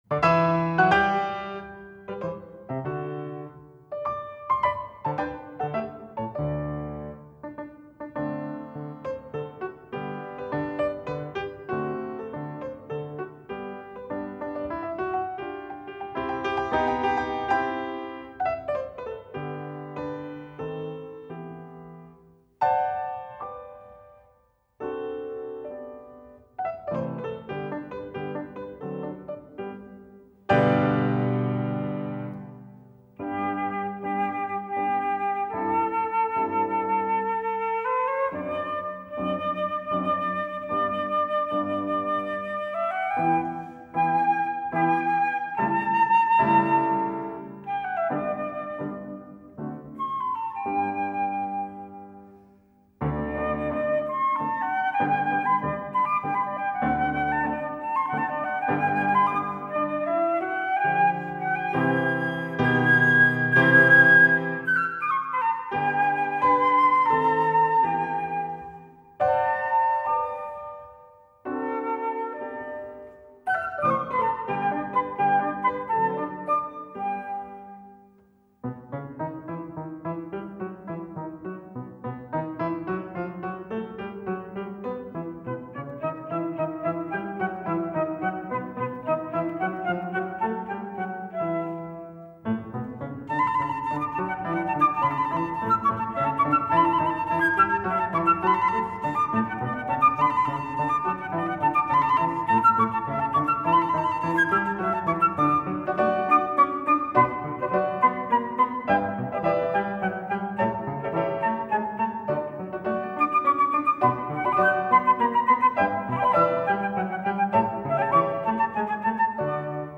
flauto
pianoforte
Musica Classica / Cameristica